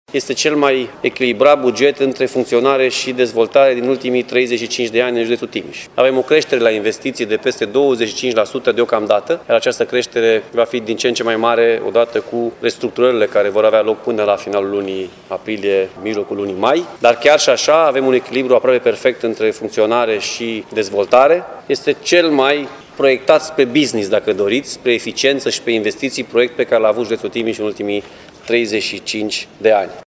Președintele Consiliului Județean Timiș, Alfred Simonis, spune că structura bugetului este concentrată pe investiții.